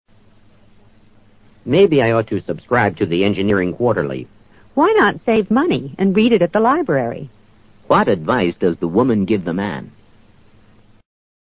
托福听力小对话【59】